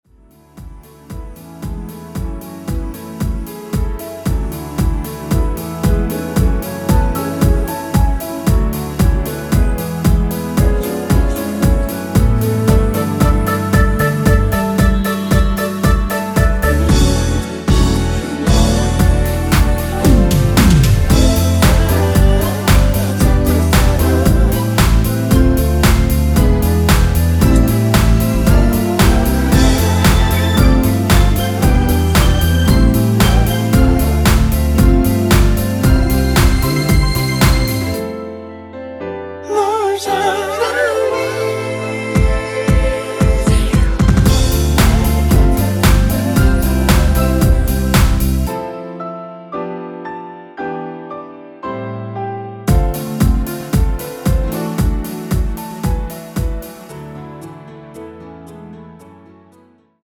원키에서(-1)내린 멜로디와 코러스 포함된 MR입니다.
앞부분30초, 뒷부분30초씩 편집해서 올려 드리고 있습니다.
중간에 음이 끈어지고 다시 나오는 이유는